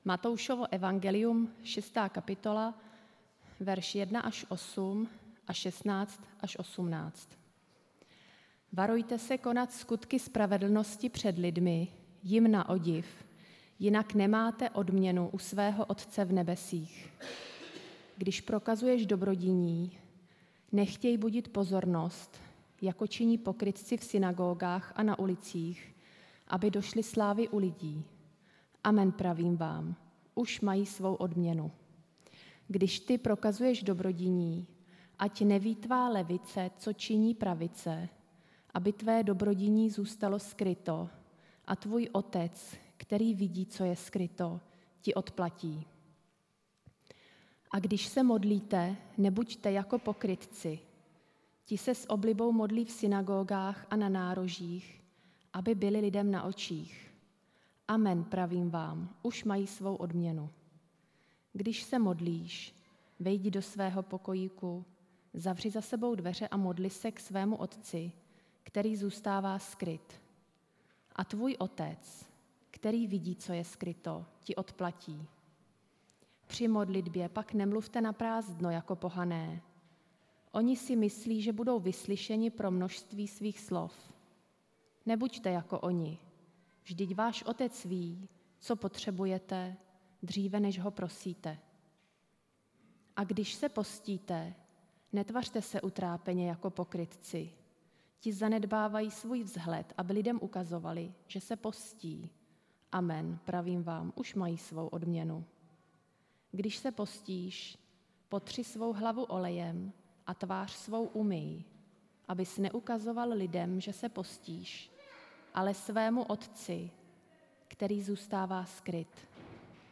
Nedělní kázání – 14.11.2021 Pravá zbožnost